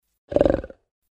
Хрюкнул слегка